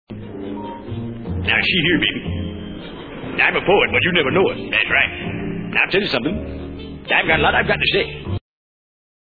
Bobby also demonstrated his versatility in this hour with his singing, impressions, acting and playing the guitar and piano.